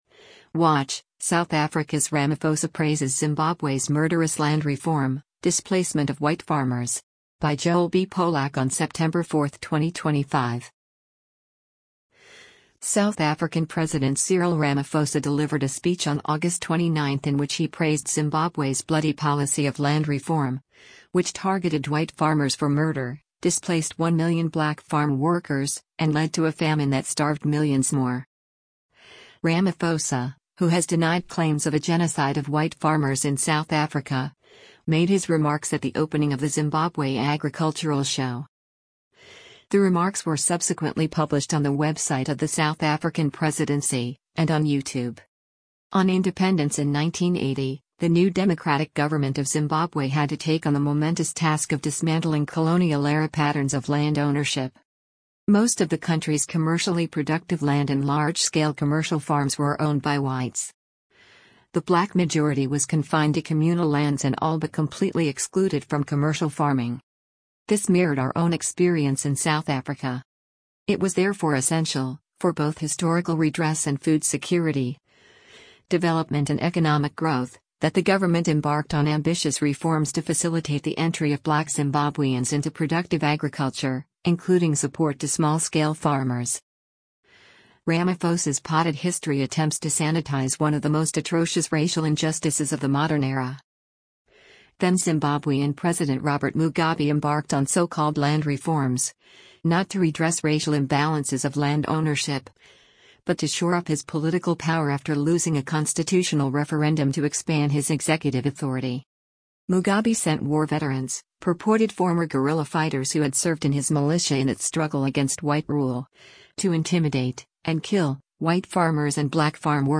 South African President Cyril Ramaphosa delivered a speech on August 29 in which he praised Zimbabwe’s bloody policy of “land reform,” which targeted white farmers for murder, displaced one million black farm workers, and led to a famine that starved millions more.
Ramaphosa, who has denied claims of a “genocide” of white farmers in South Africa, made his remarks at the opening of the Zimbabwe Agricultural Show.